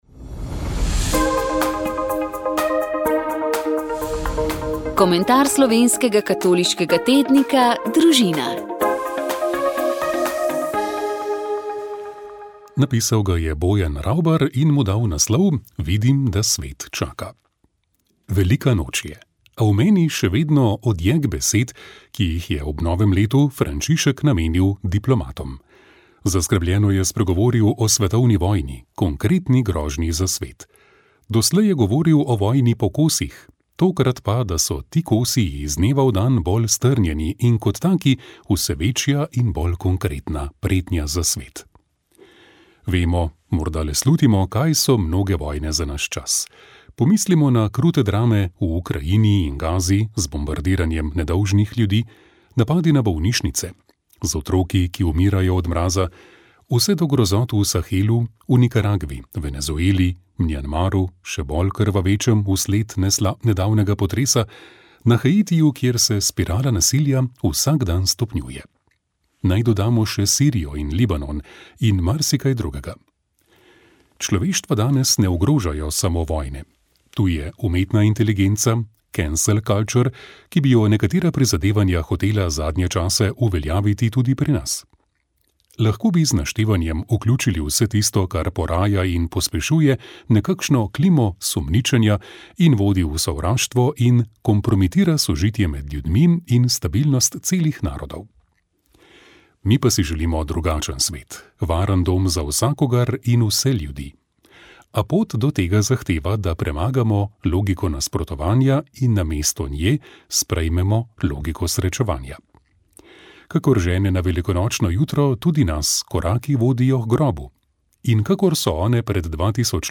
Na voljo so različni romarski paketi, zato vabijo, da si na njihovih spletnih straneh izberete svojega in se prijavite najpozneje do konec decembra, ker vas bo stalo manj. V pogopvoru